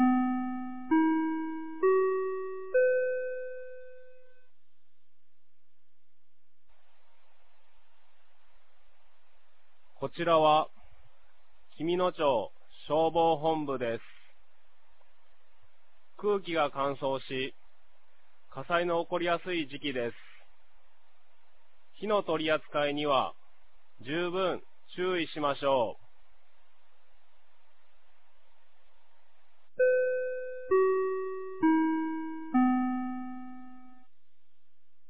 2026年01月31日 16時00分に、紀美野町より全地区へ放送がありました。